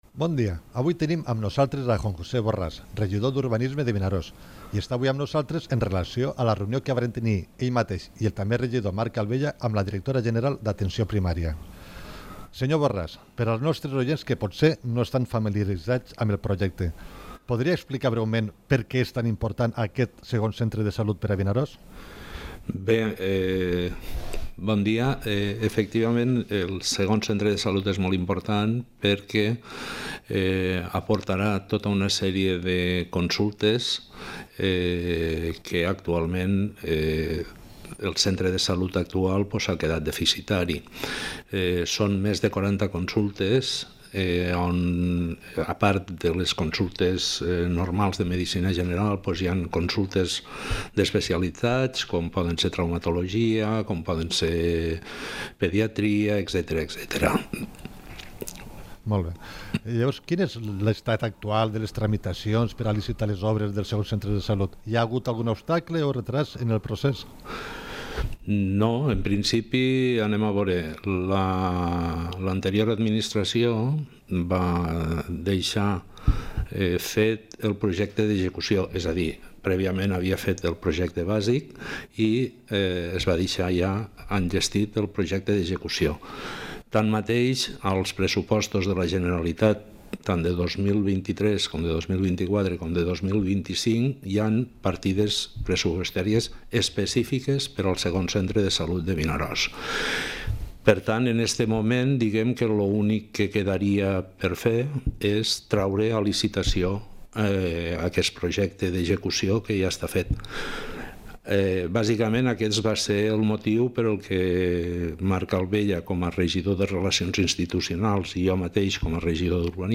Entrevista al regidor d'Urbanisme de Vinaròs, Juan José Borràs, pel segon Centre de Salut de Vinaròs